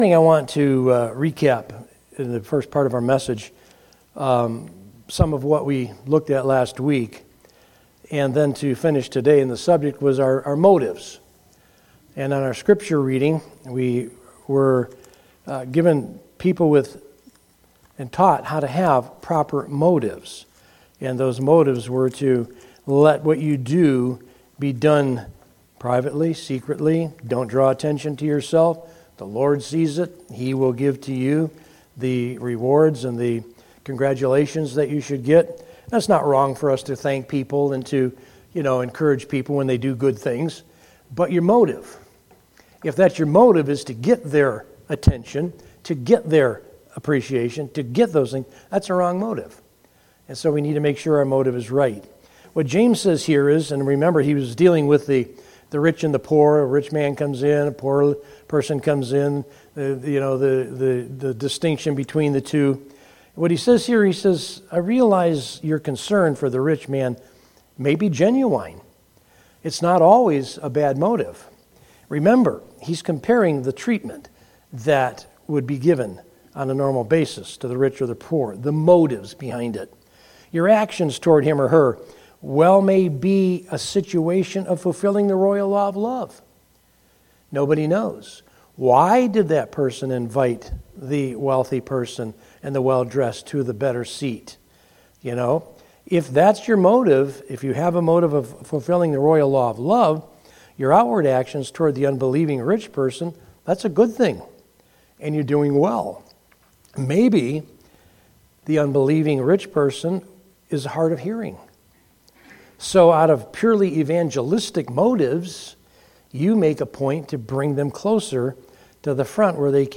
From Series: "Sunday Morning - 11:00"
Sermon